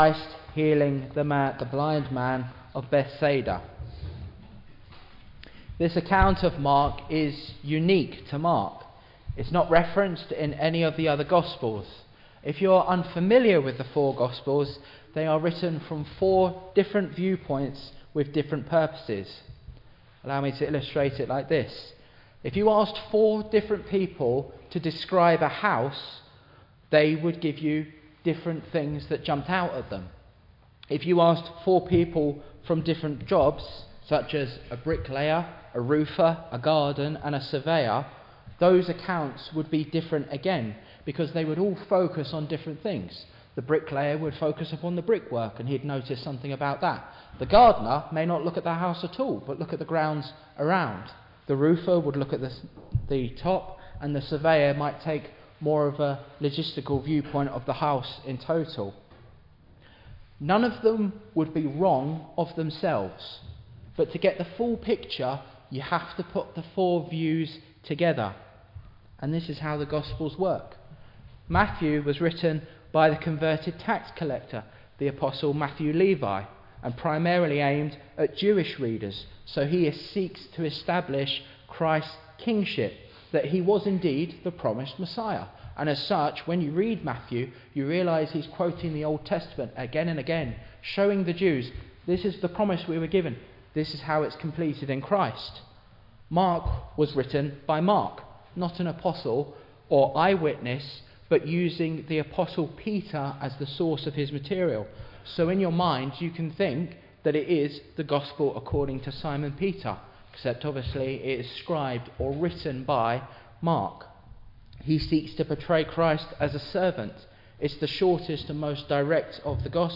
Teaching and Gospel sermons from the Gospel of Mark